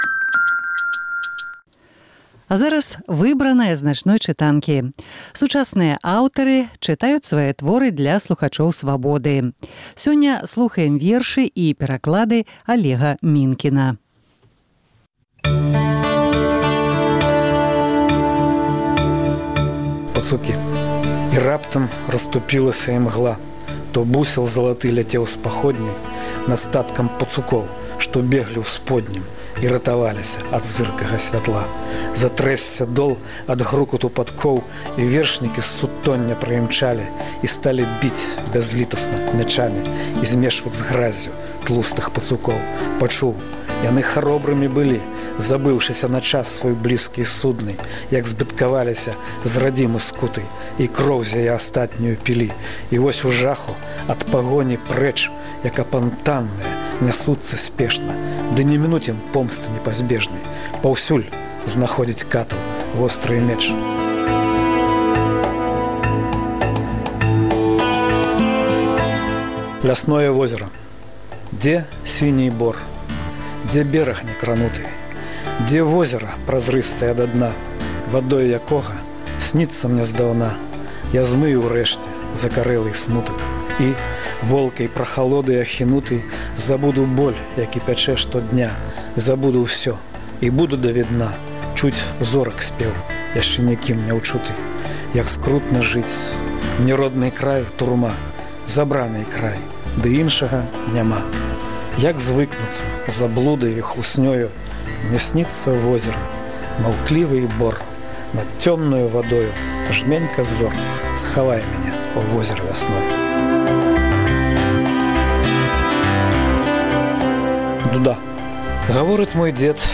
Мы паўтараем перадачы з архіву Свабоды. У «Начной чытанцы» — 100 сучасных аўтараў чыталі свае творы на Свабодзе.